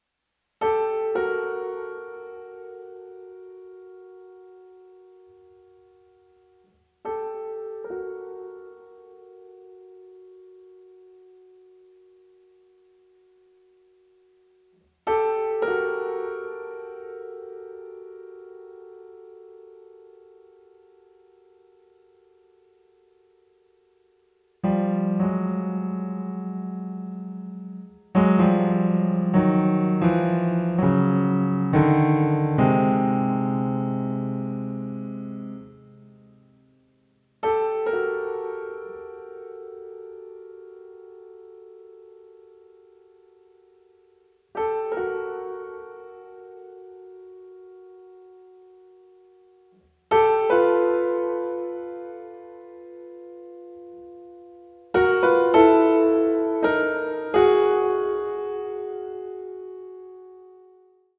solo album